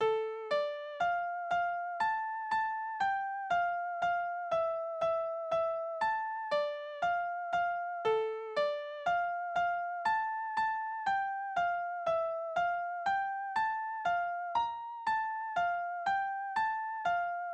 Balladen: Der Tod des Fähnrichs
Tonart: D-Moll
Tonumfang: große None